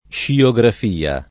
sciografia [ + šio g raf & a ] s. f.